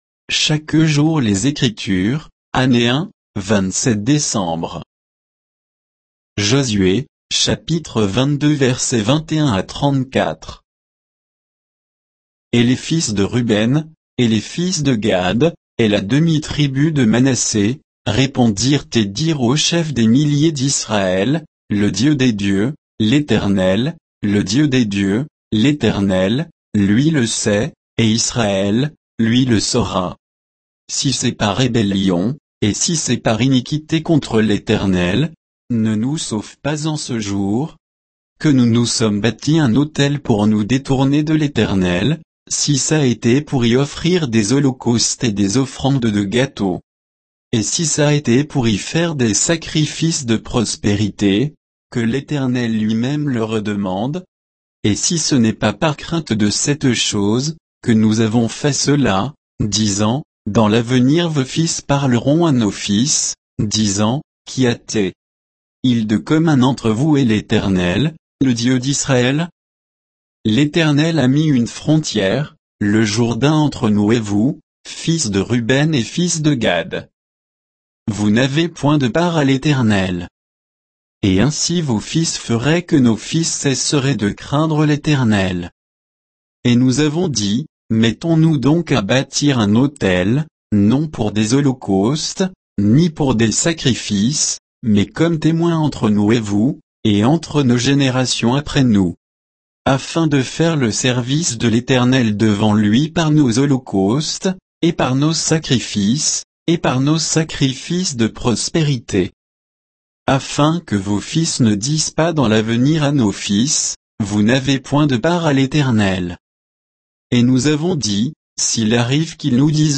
Méditation quoditienne de Chaque jour les Écritures sur Josué 22